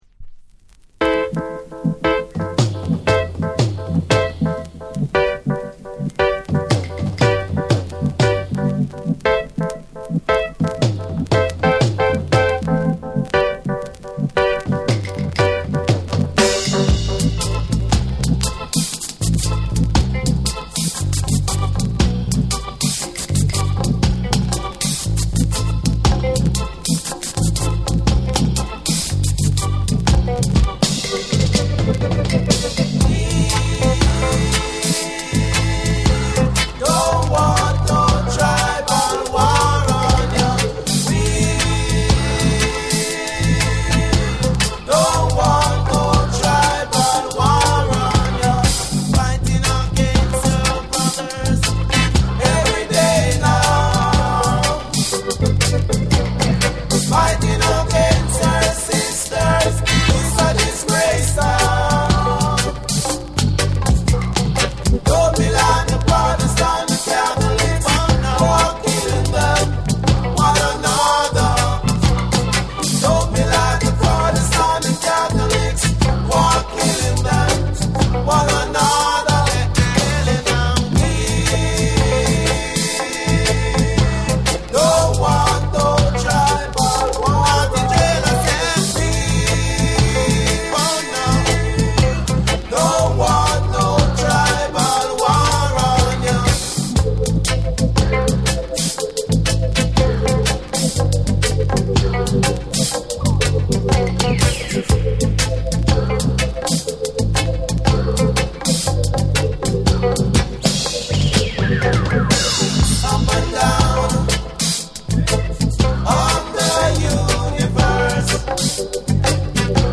ロックステディの甘美なメロディーと、初期レゲエのリズミックな躍動感が見事に融合したサウンドが魅力